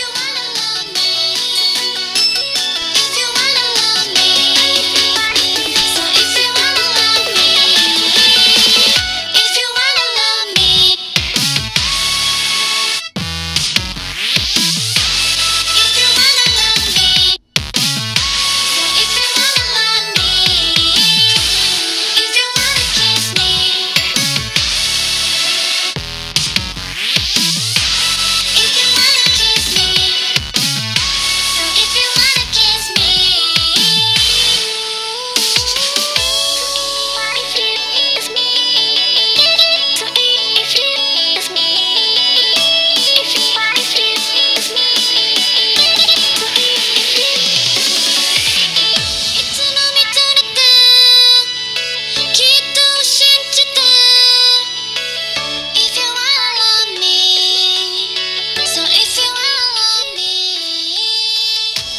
0分50秒あたりから1分50秒くらいまでの約1分間を録音して比較しました。
録音機材：OLYMPUS LINEAR PCM RECORDER LS-20M
JBL EVEREST 100 Bluetooth イヤホン：WAVはこちら>>>
レコーダーのマイクは比較的高音域の感度が高いので、一般的にはキンキンした音になりがちです。
最も高価JBLのBluetoothはやはりやや音圧が低く迫力に欠けますが、全体的に音が繊細で高級感があります。